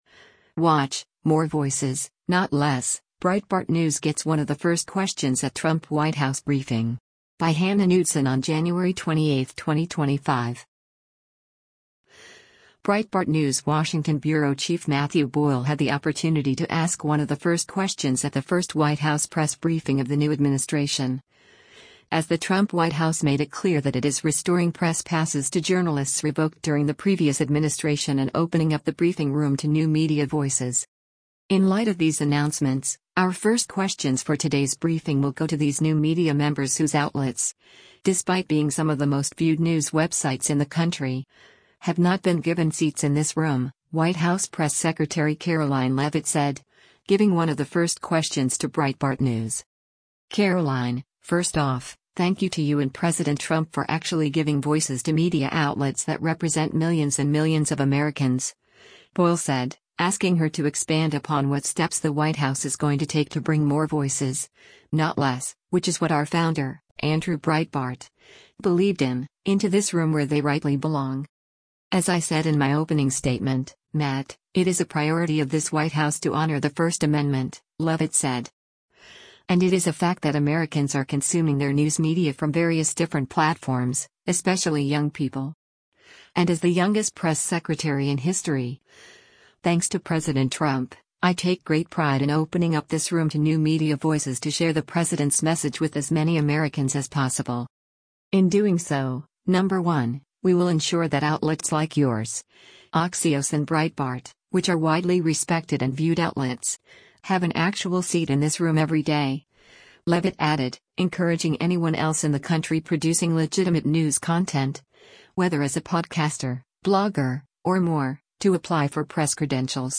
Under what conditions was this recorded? WATCH—‘More Voices, Not Less’: Breitbart News Gets One of the First Questions at Trump White House Briefing